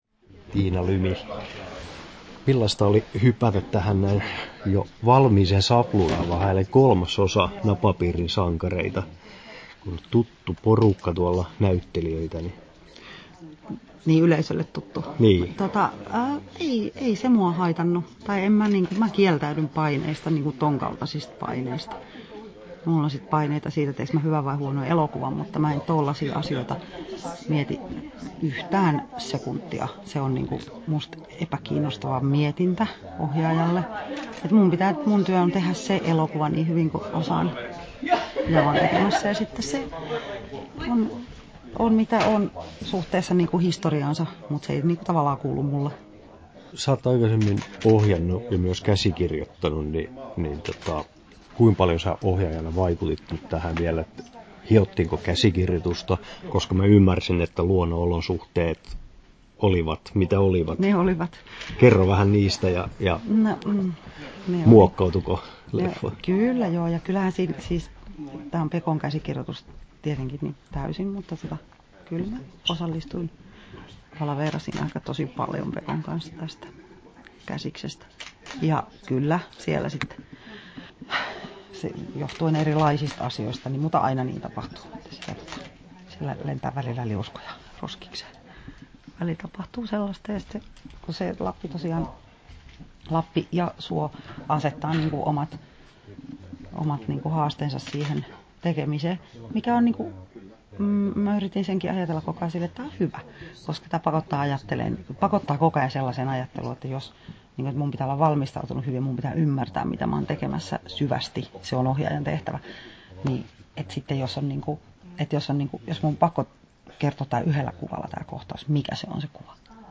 Tiina Lymi ja Napapiirin sankarit 3 • Haastattelut
Haastattelussa Tiina Lymi Kesto: 7'06" Tallennettu: 08.08.2017, Turku Toimittaja